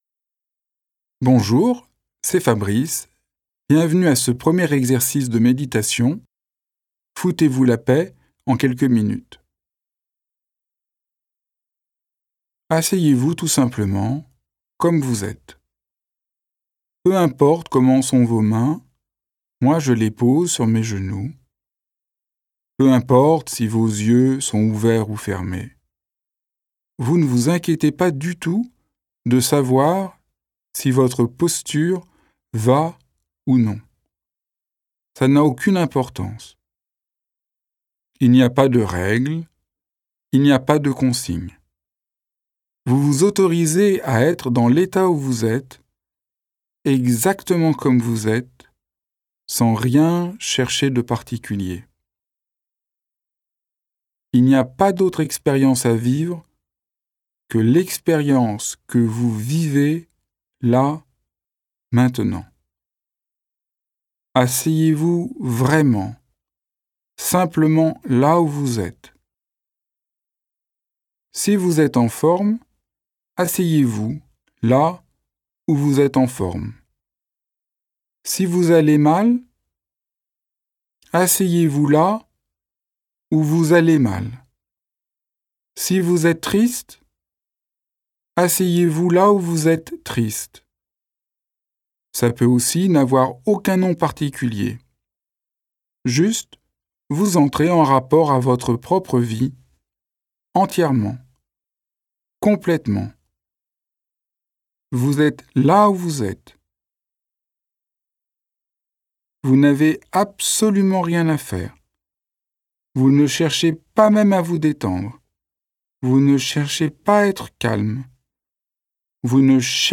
Foutez-vous la paix et commencez à vivre de Fabrice Midal (+2 méditations offertes)- méditations guidées-Développement personnel-concours
04-1ere-Meditation-Foutez-vous-la-paix-en-quelques-minutes.mp3